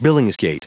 Prononciation du mot : billingsgate
billingsgate.wav